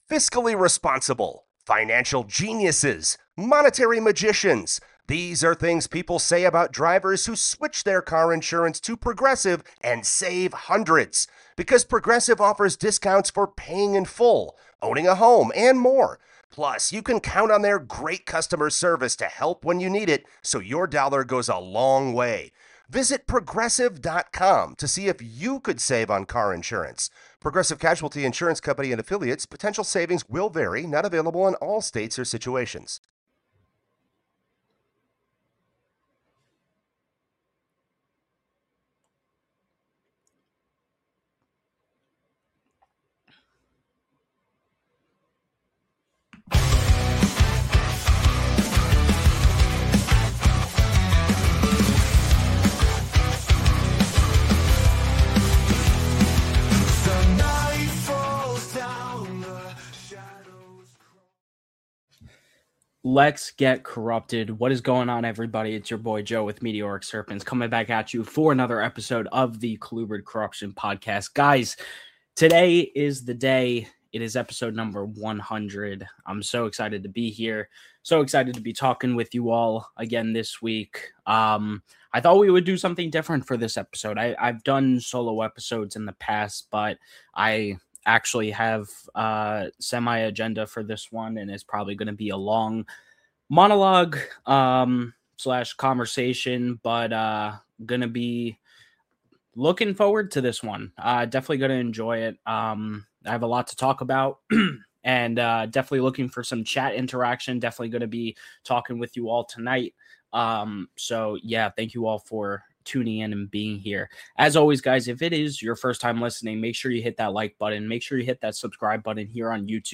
The POWERFUL Porphyracea Roundtable